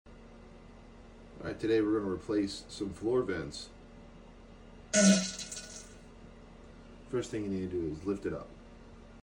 Handyman work on house floor sound effects free download